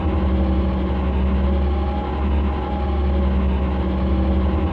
liftmoving.ogg